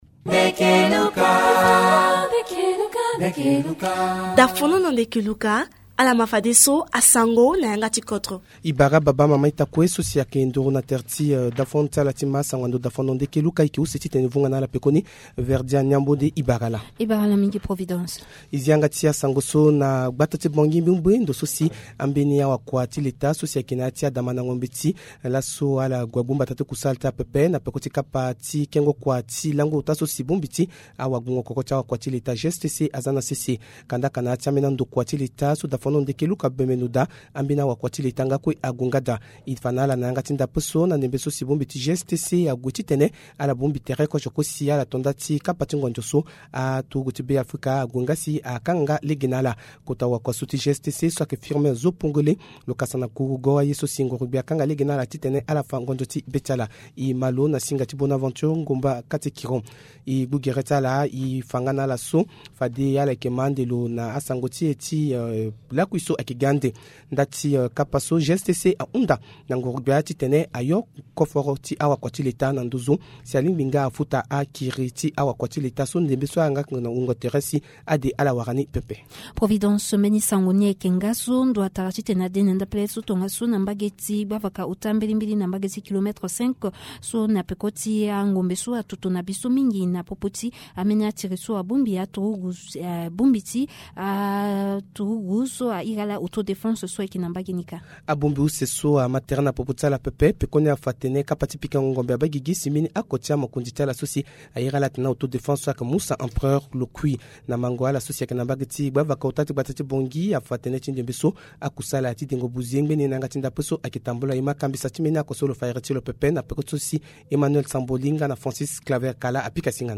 Journal en Sango